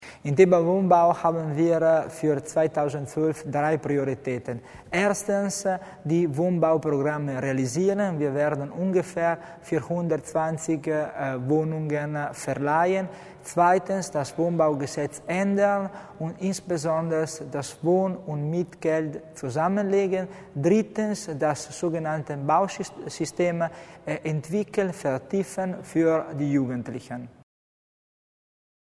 Landesrat Tommasini über den Schwerpunkt Wohnbau
LR Tommasini bei der Budgetvorstellung für den Wohnbaubereich.